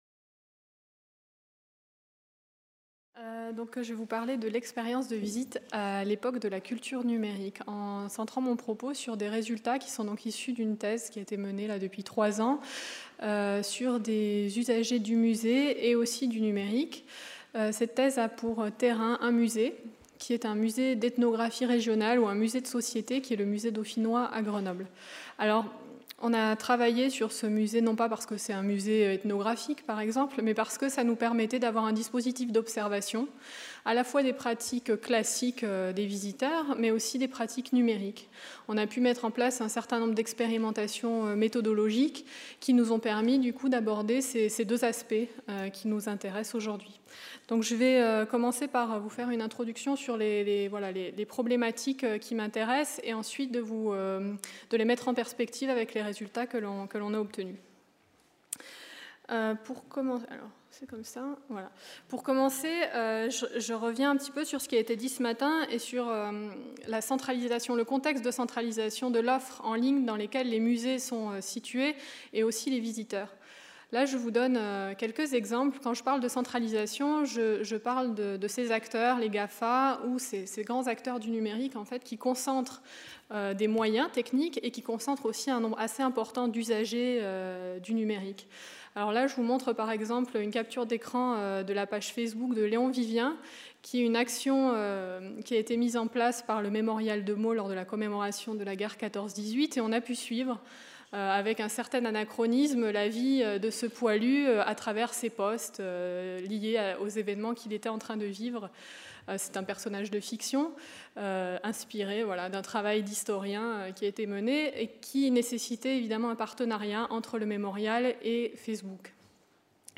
Colloque organisé par le PREAC art contemporain le 20 novembre 2015 à l’Auditorium des Champs Libres